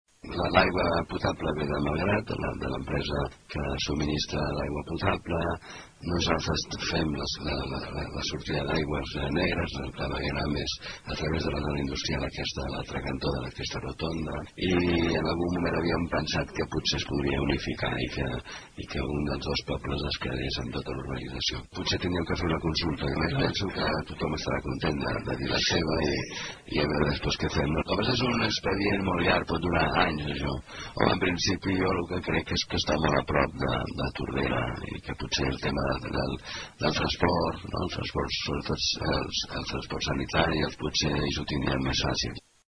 En una entrevista al programa “La tarda és temps era temps” de Ràdio Tordera, l’alcalde palafollenc, Valentí Agustí, va proposar fer una consulta popular als veïns de la urbanització per tal que la zona passi a ser gestionada només per un dels dos municipi i així deixi de ser compartida.